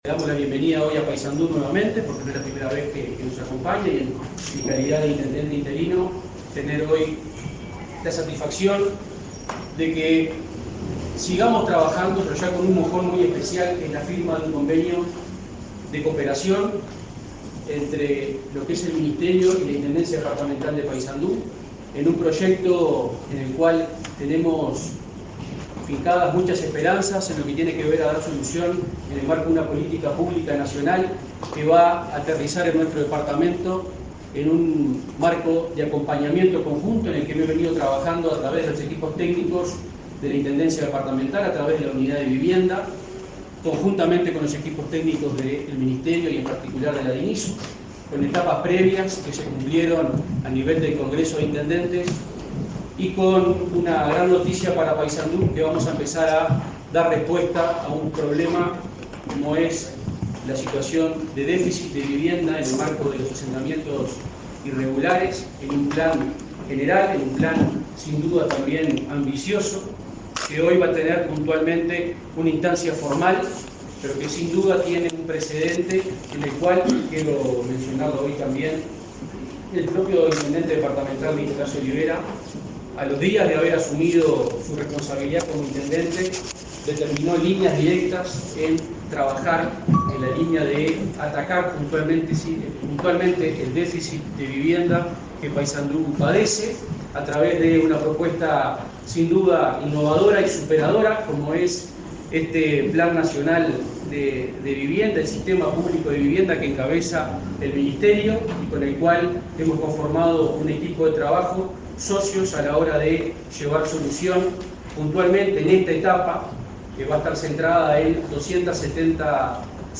Palabras de autoridades en Paysandú en firma de plan Avanzar